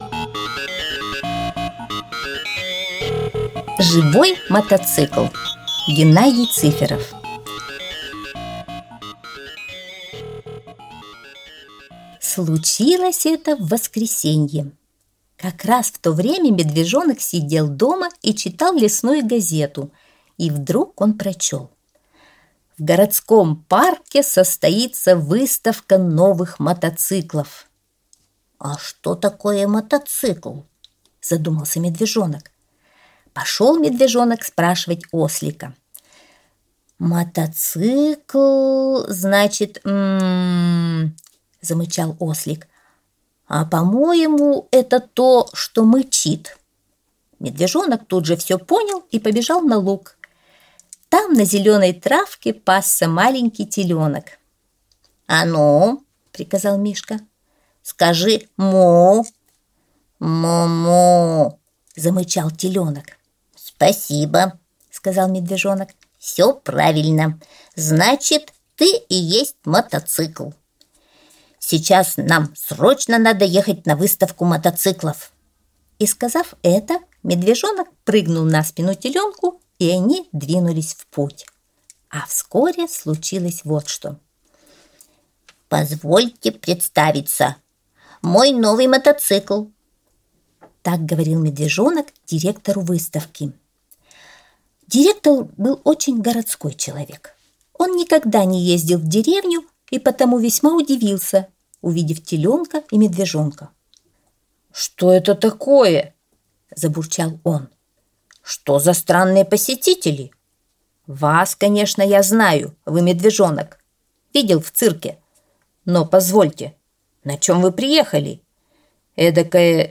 Живой мотоцикл - аудиосказка Геннадия Цыферова - слушать онлайн